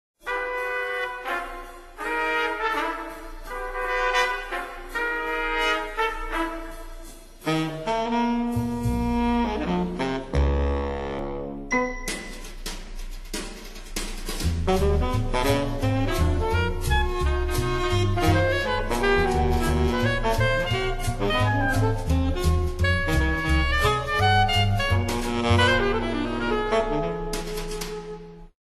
Abstrakte Ballettmusik, geeignet für verschiedene Libretti